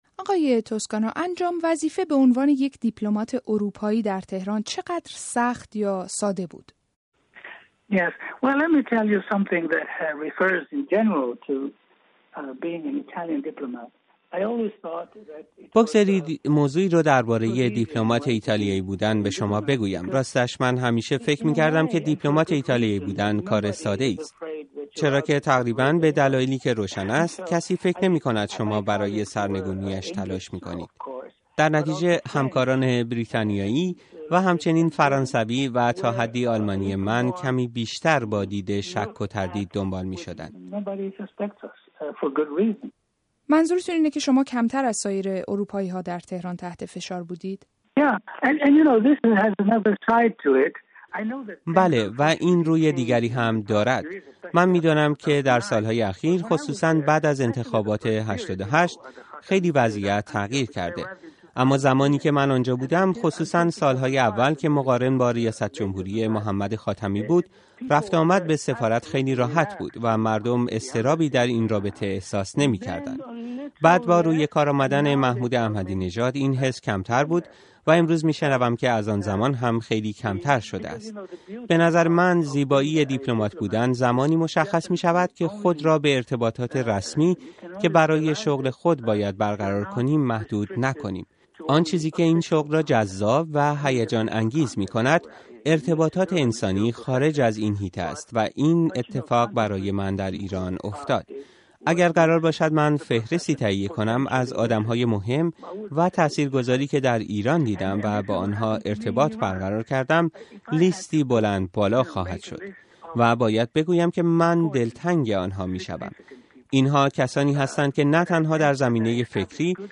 مصاحبه رادیو فردا با روبرتو توسکانو، سفیر پیشین ایتالیا در تهران-قسمت دوم